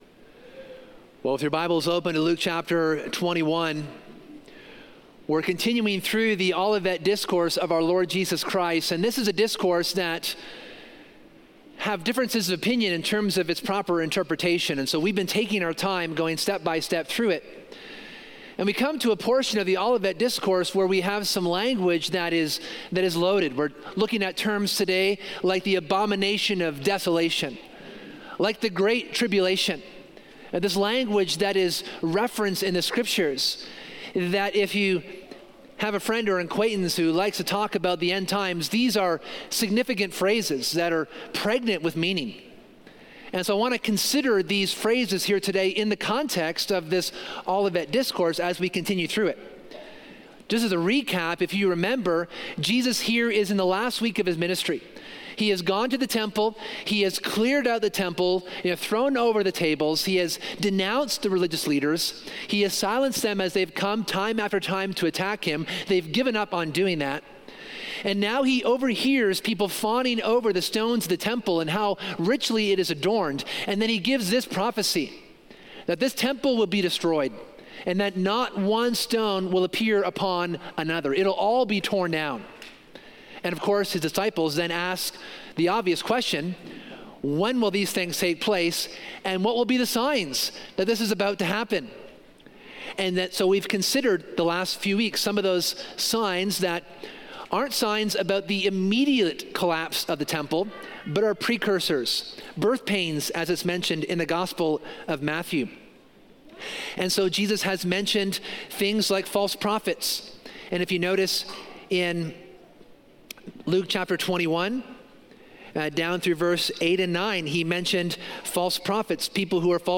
In this sermon we continue our exposition of the Olivet Discourse.